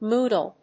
ムードル